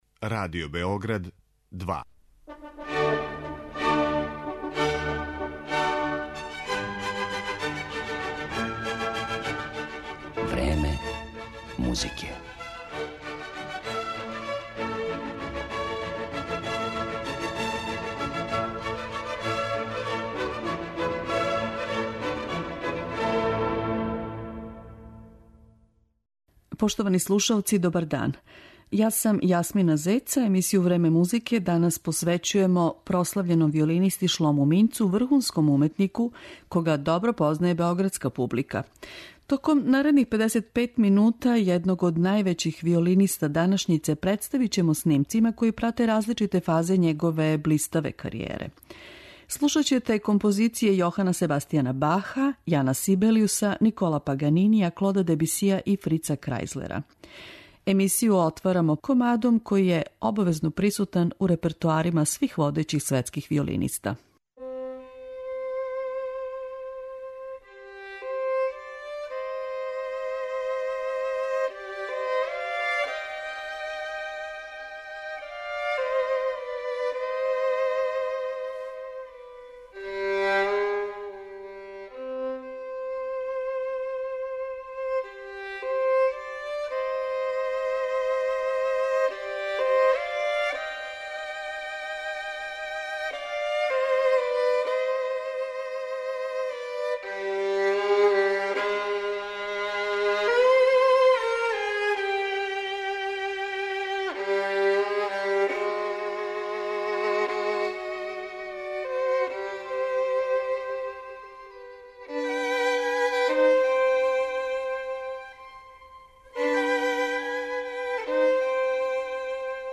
Емисију посвећујемо прослављеном виолинисти Шлому Минцу, врхунском уметнику кога добро познаје и београдска публика.